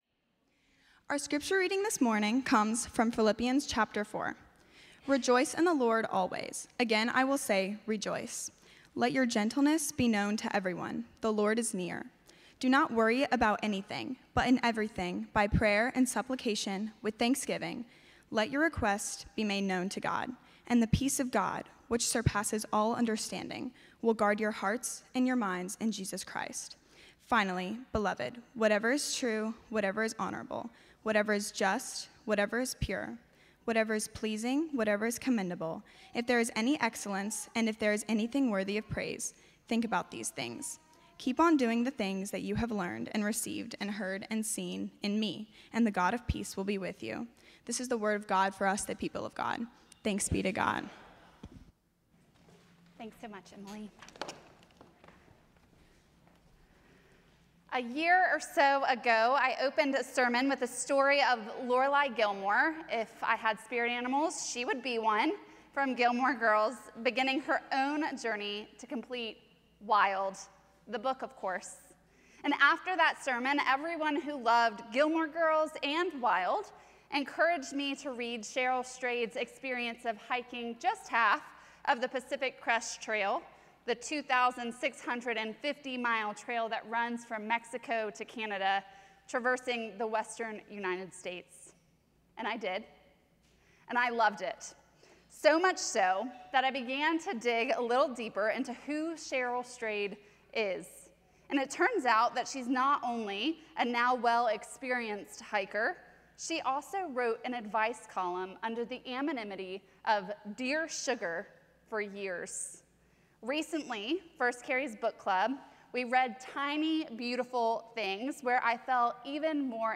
First Cary UMC's First on Chatham Sermon